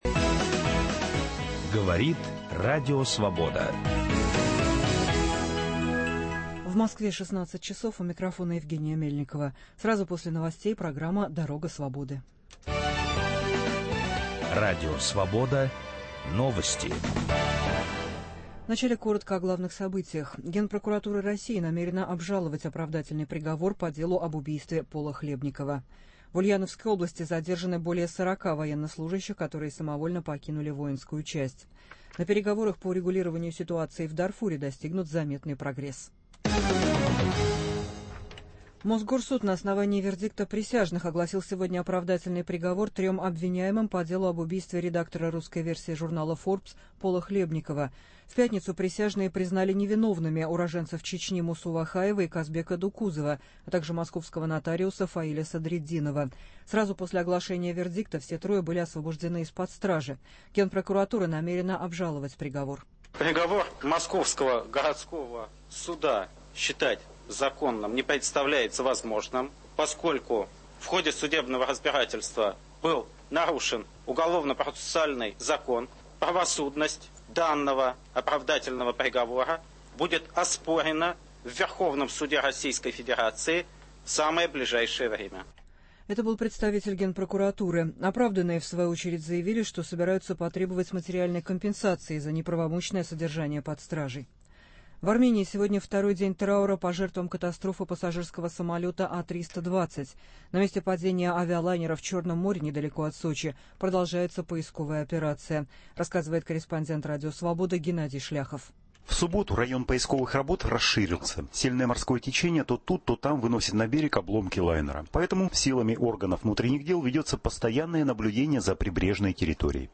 Как нарушаются права работников в частном секторе и в государственных учреждениях. Региональные репортажи.